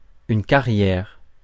Ääntäminen
IPA: /ka.ʁjɛʁ/